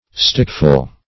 Search Result for " stickful" : The Collaborative International Dictionary of English v.0.48: Stickful \Stick"ful\, n.; pl. Stickfuls .